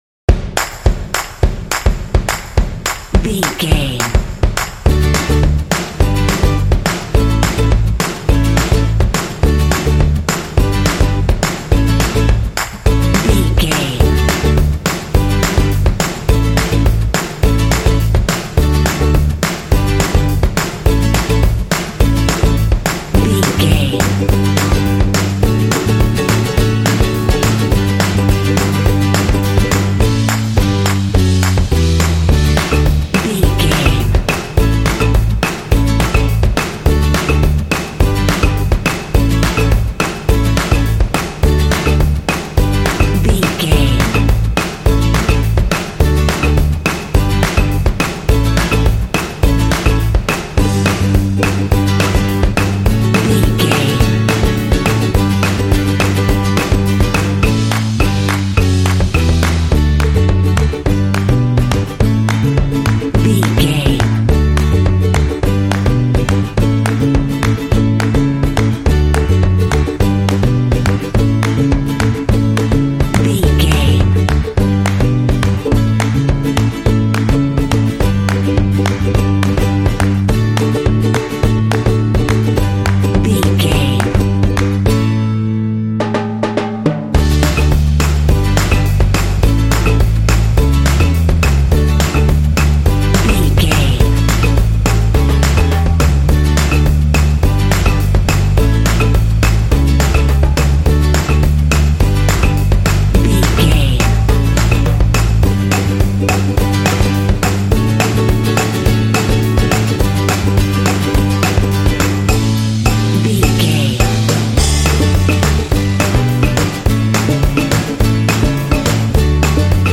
Uplifting
Ionian/Major
D
bright
joyful
energetic
drums
percussion
acoustic guitar
bass guitar
strings
contemporary underscore